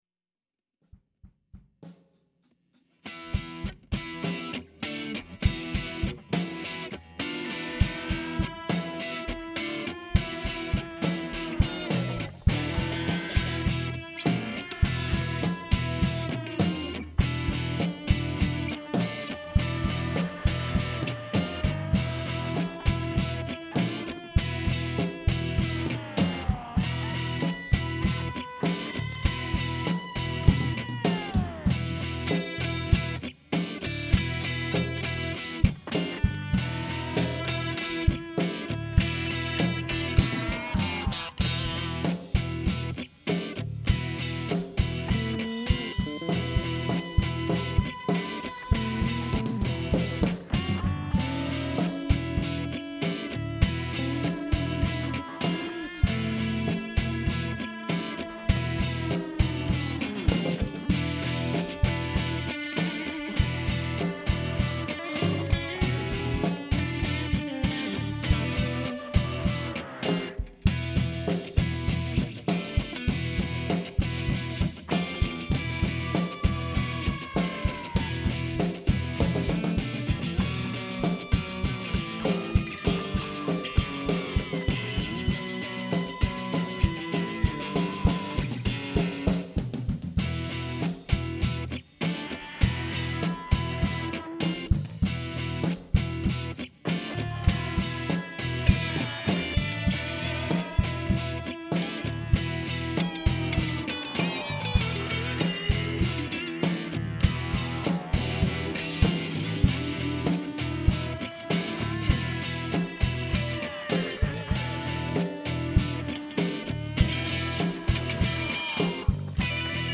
We forgot to turn the phantom power on the overhead drum mics – so all you get is the close mics on each shell.
slide guitar